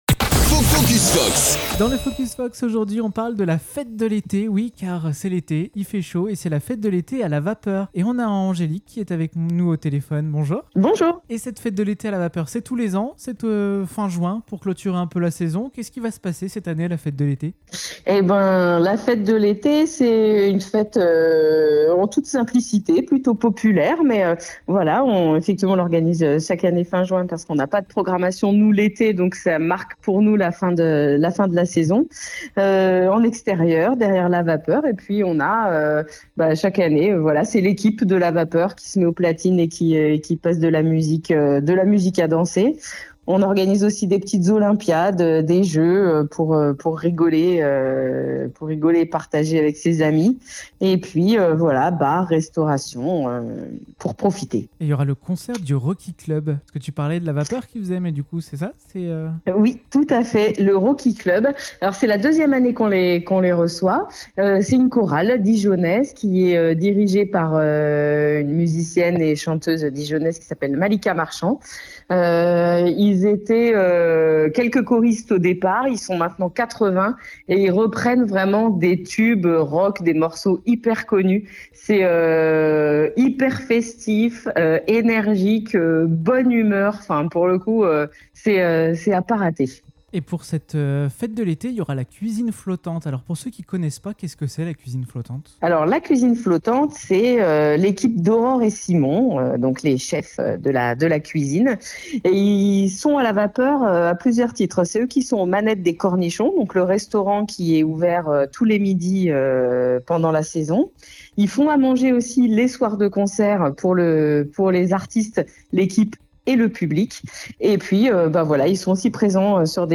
???? Par téléphone